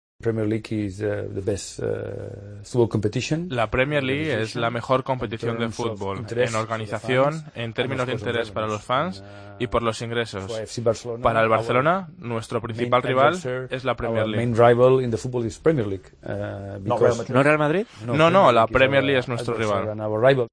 El presidente del Barcelona en una entrevista en la BBC habla de los rivales del Barcelona: La Premier League es la mejor comptición.